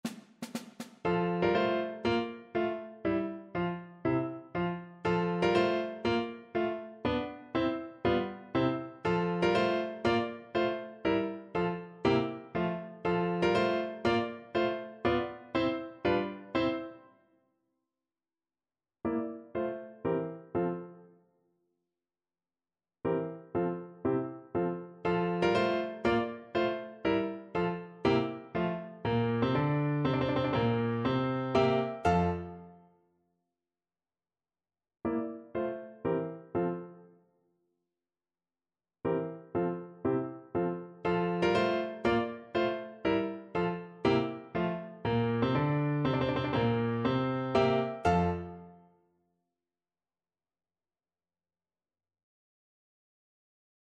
Schumann: Marsz żołnierski (na klarnet i fortepian)
Symulacja akompaniamentu